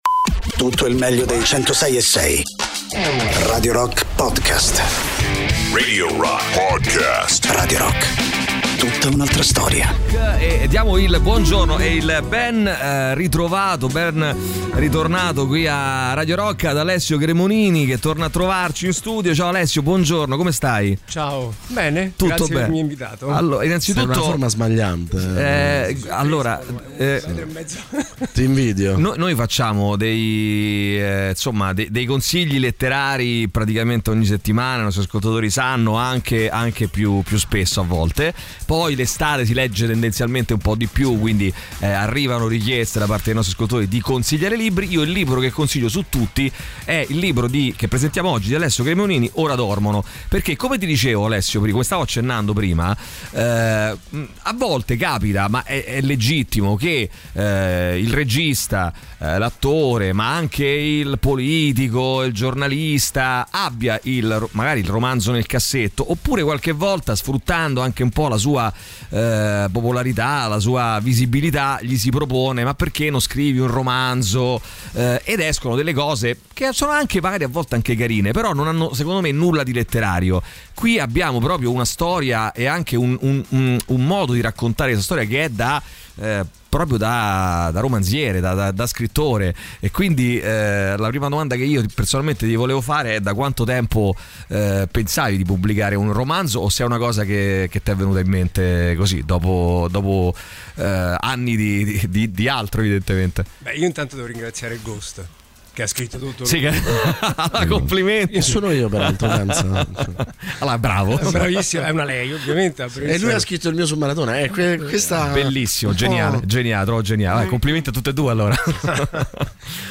Interviste: Alessio Cremonini (14-07-23)
ospite in studio